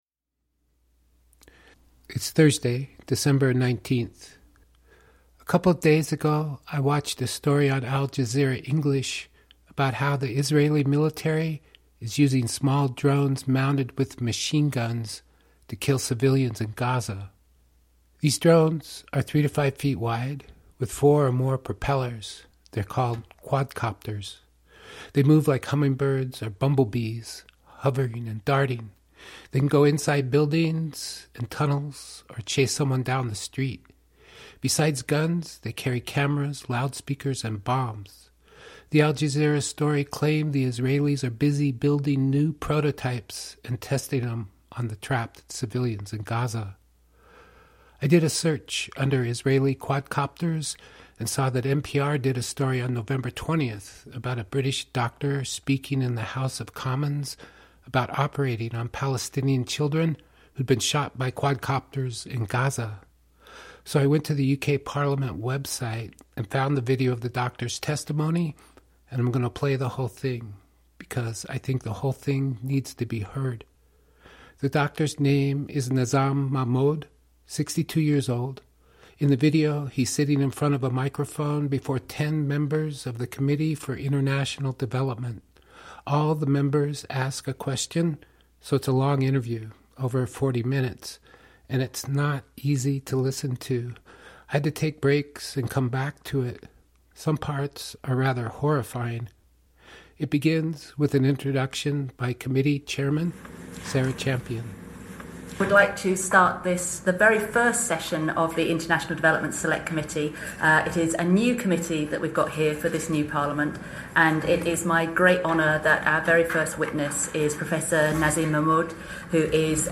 In the video, 1:19.9 he's sitting in front of a microphone before 10 members of the Committee for International 1:24.6 Development. All the members ask a question, so it's a long interview, 1:29.9 over 40 minutes, and it's not easy to listen to.